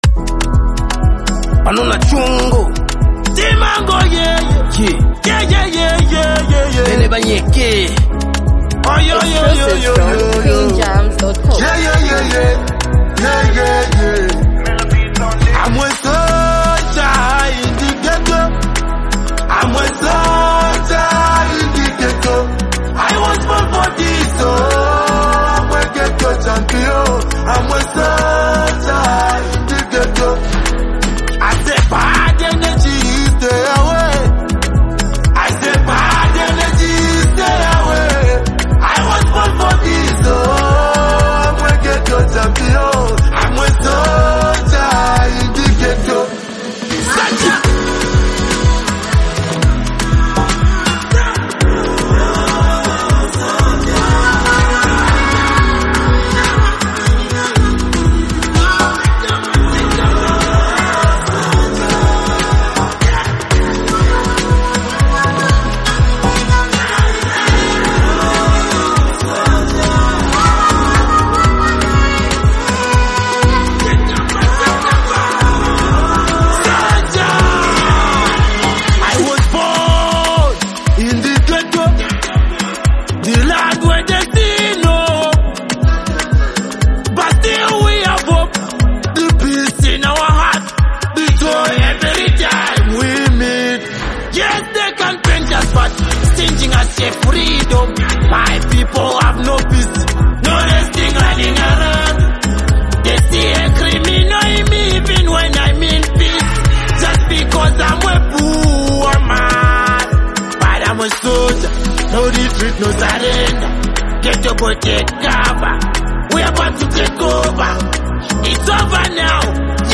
complements the track with his sharp, confident rap delivery